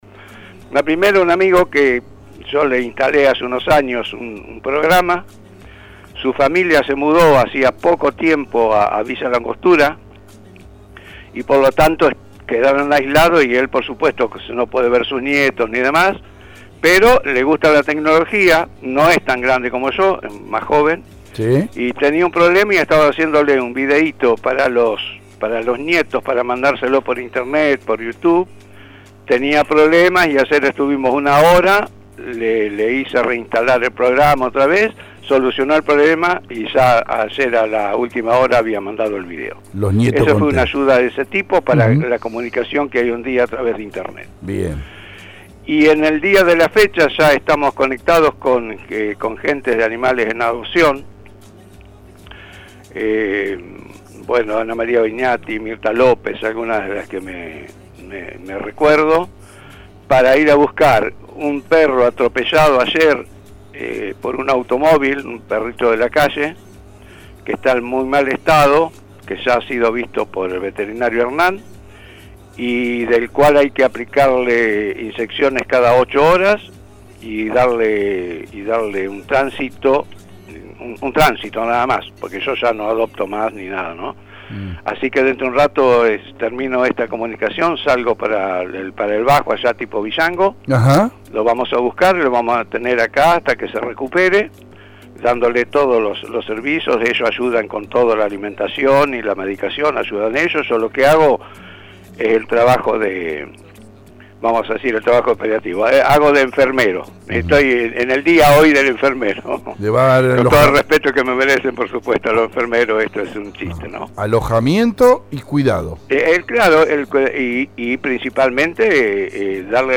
CON ZETA 1973: El resumen completo de los cuatro protagonistas del programa en radio EL DEBATE, del pasado viernes - EL DEBATE